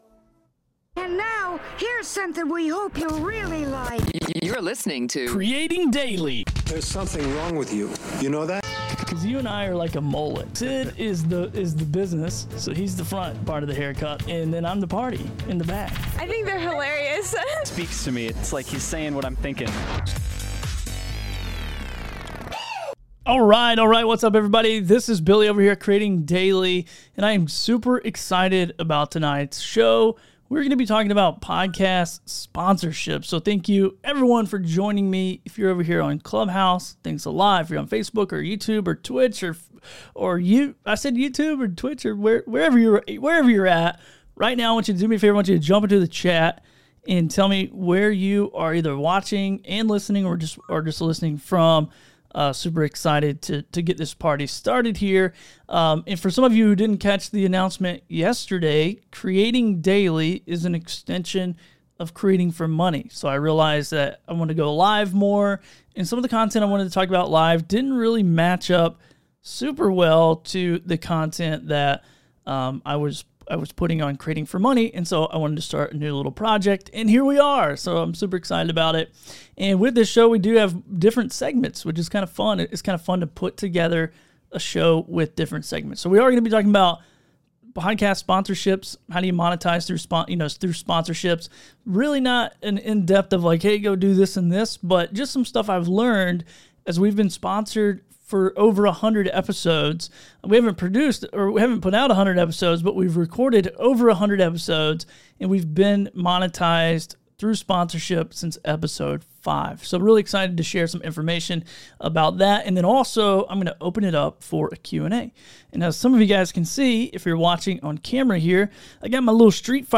In this live stream/episode I share some of my best takeaways.